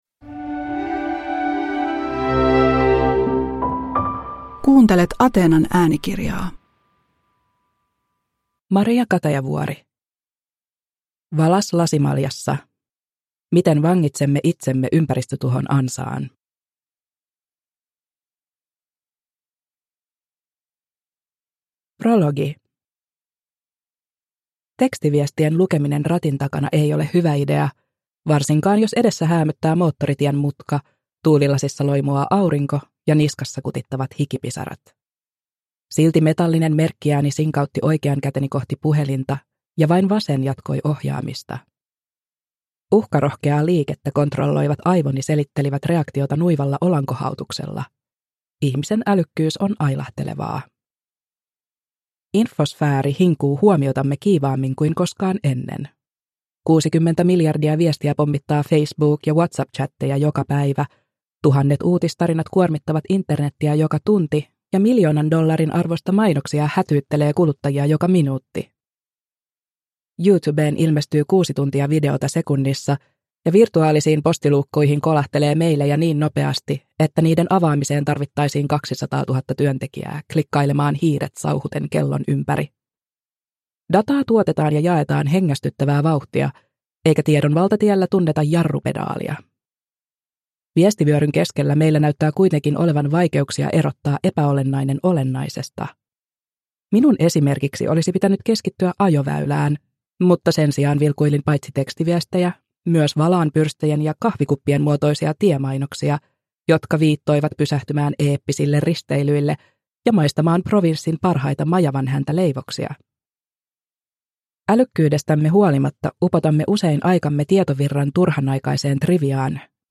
Valas lasimaljassa – Ljudbok – Laddas ner